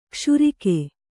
♪ kṣurike